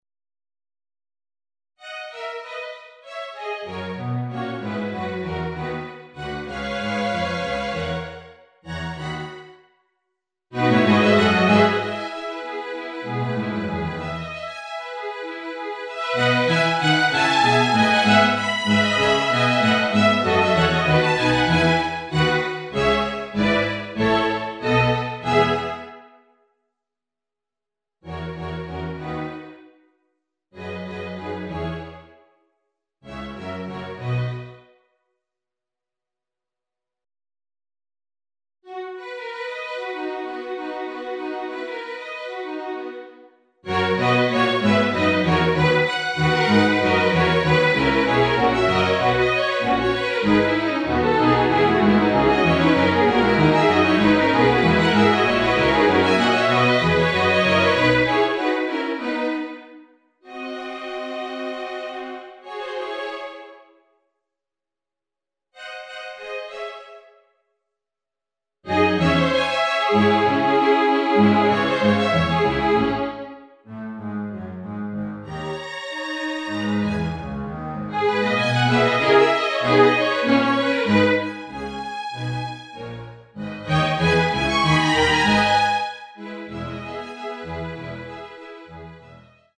Si tratta di basi orchestrali, cameristiche e pianistiche.
BASI ORCHESTRALI